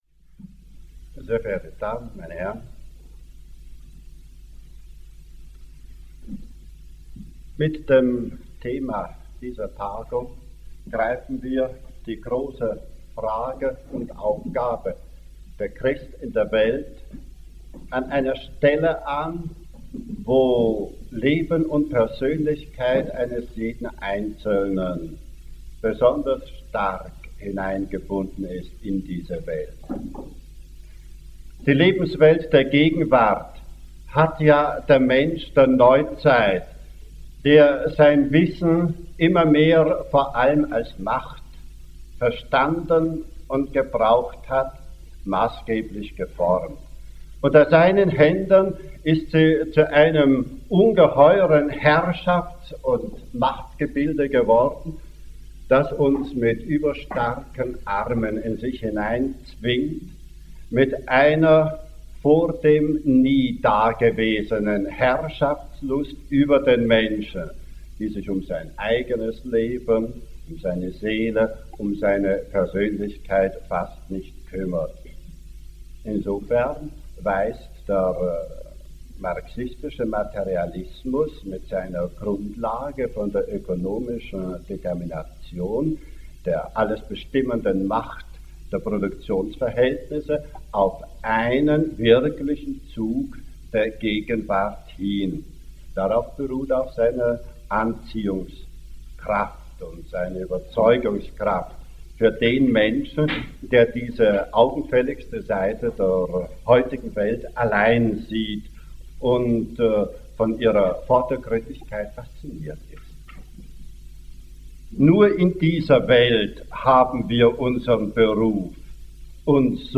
Christliches Maß beruflicher Tüchtigkeit - Rede des Monats - Religion und Theologie - Religion und Theologie - Kategorien - Videoportal Universität Freiburg